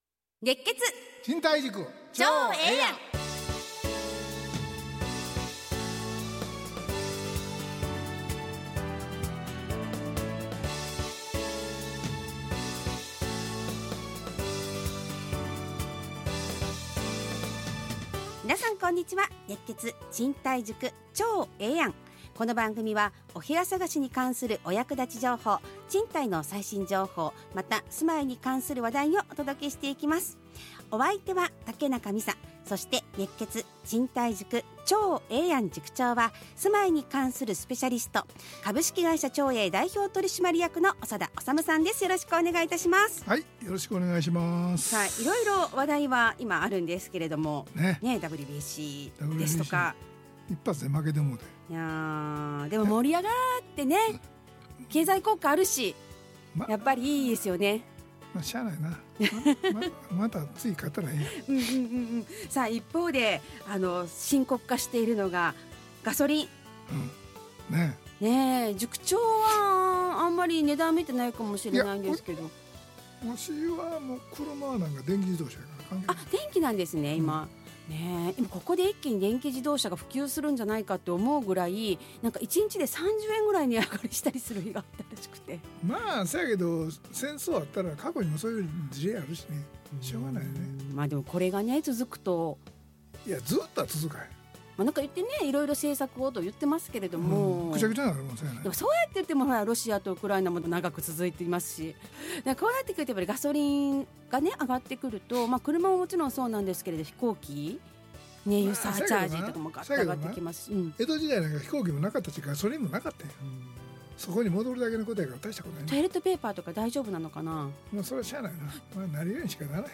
ラジオ放送 2026-03-23 熱血！